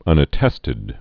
(ŭnə-tĕstĭd)